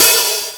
HatOpen.aif